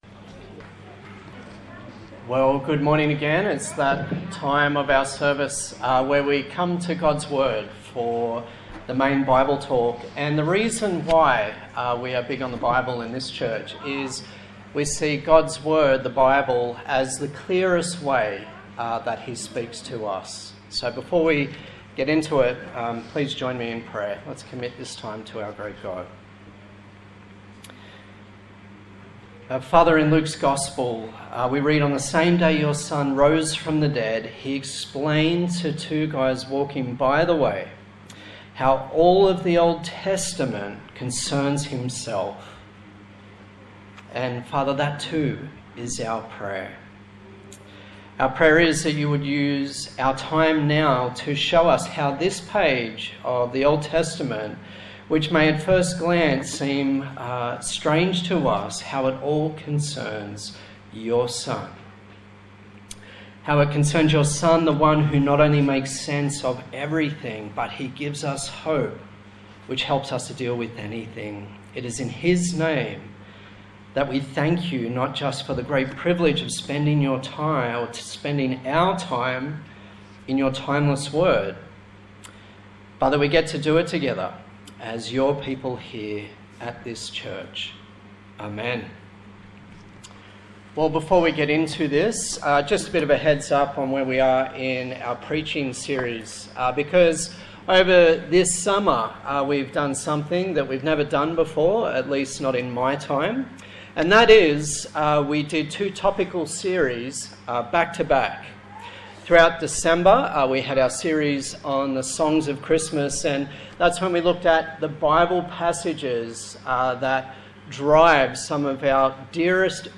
Service Type: Sunday Morning A sermon in the series on the book of Leviticus